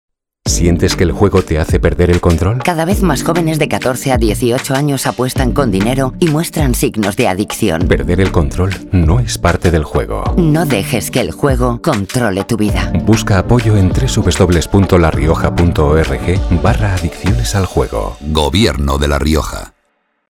Cuñas radiofónicas